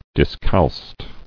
[dis·calced]